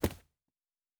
Footstep Carpet Running 1_06.wav